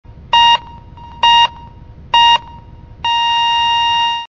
kardiogramma_24947.mp3